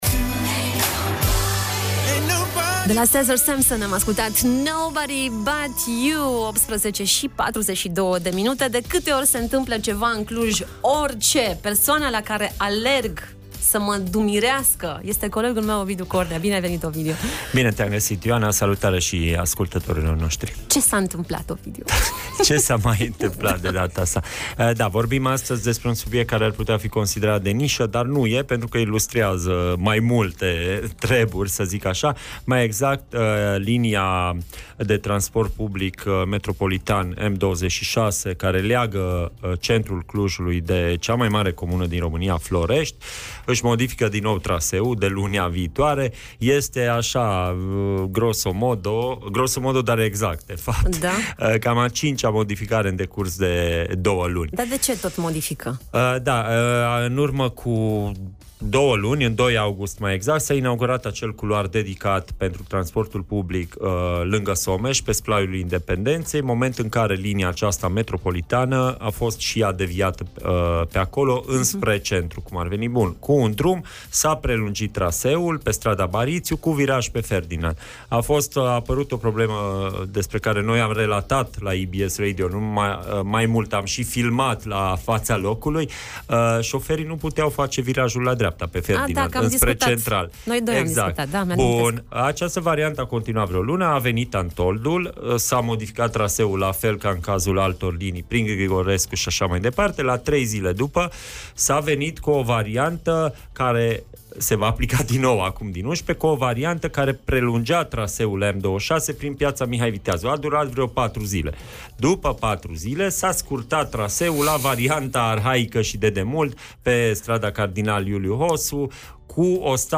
Vocea călătorilor și a… șoferilor